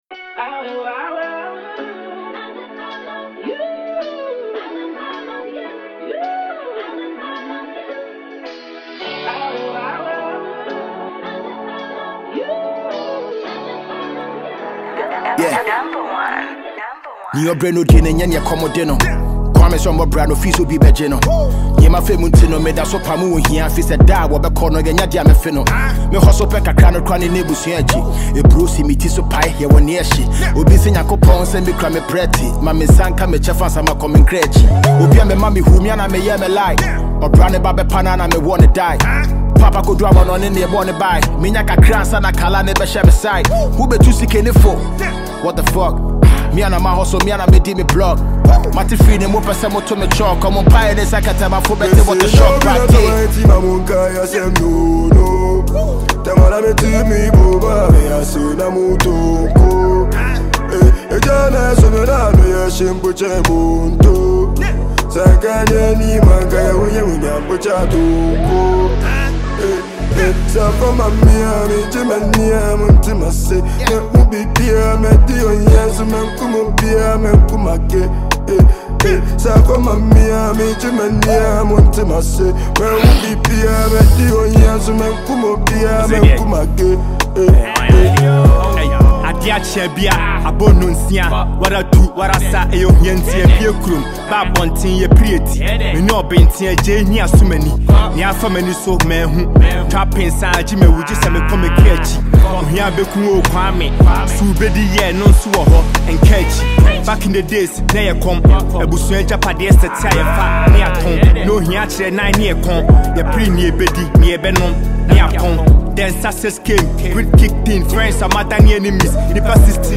a Ghanaian rapper
unapologetically authentic and musically rich